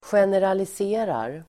Ladda ner uttalet
Uttal: [sjeneralis'e:rar]
generaliserar.mp3